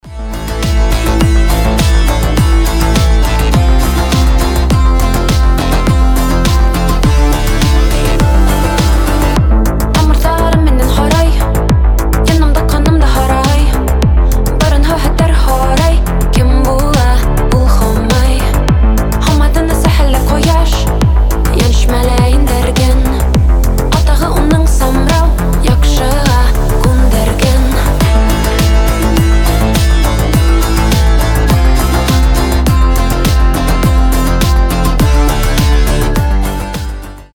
Танцевальная громкая мелодия на звонок.